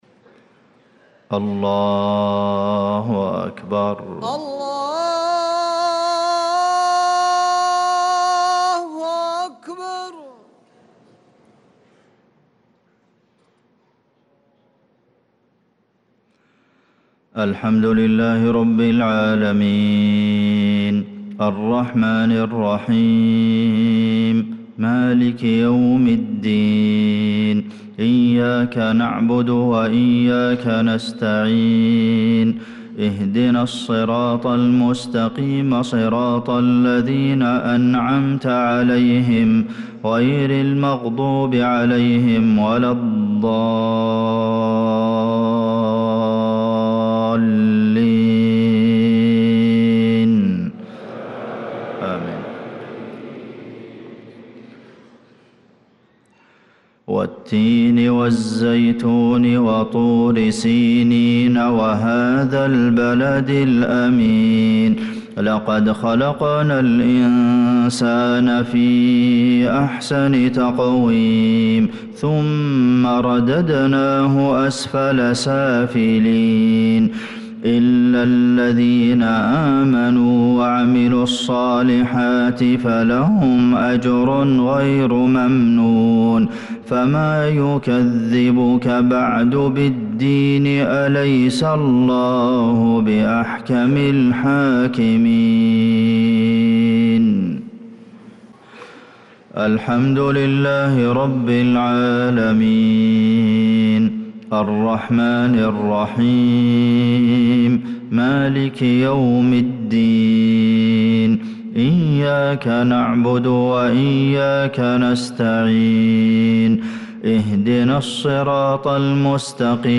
صلاة المغرب للقارئ عبدالمحسن القاسم 20 ربيع الآخر 1446 هـ